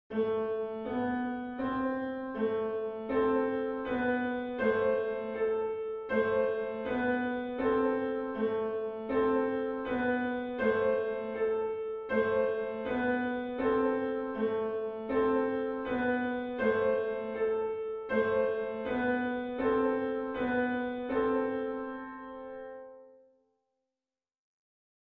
リピートは基本的に省略しています